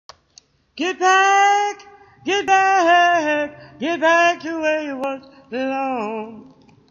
Ran Out Of Air At End
I also ended up getting most of his normal singing voice.